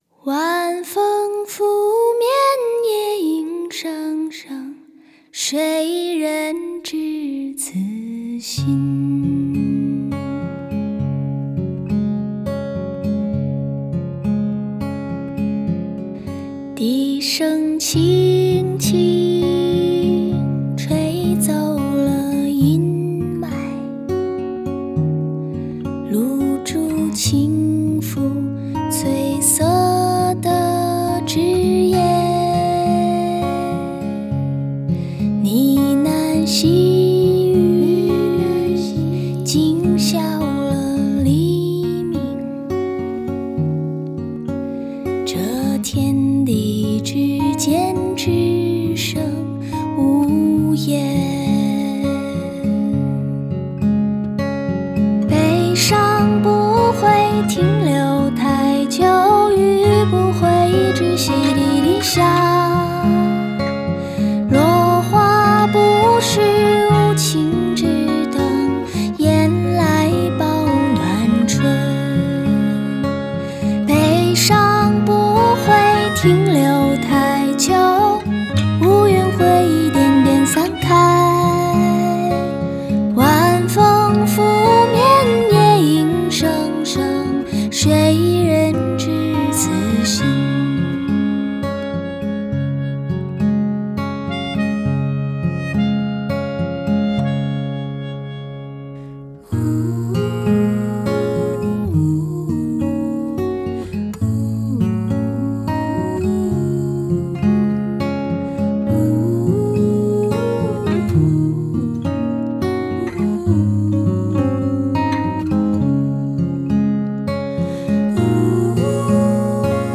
创作时将京剧唱腔与民谣结合